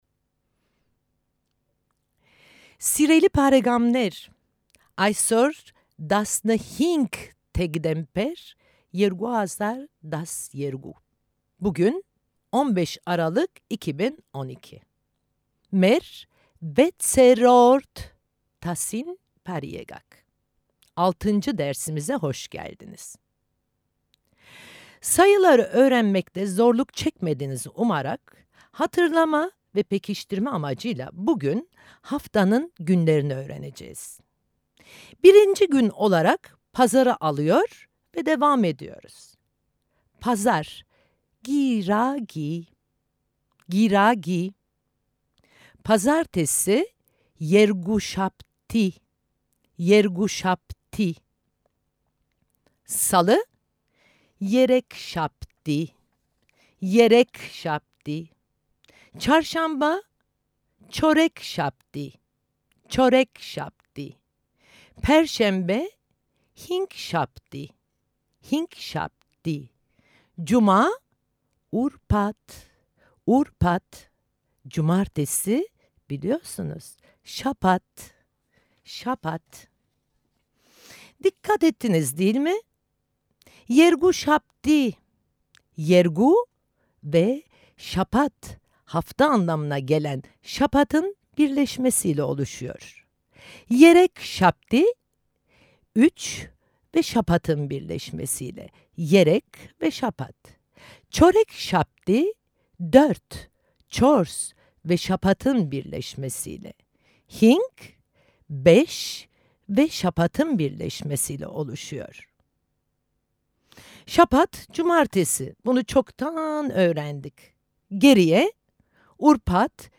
Ermenice dersleri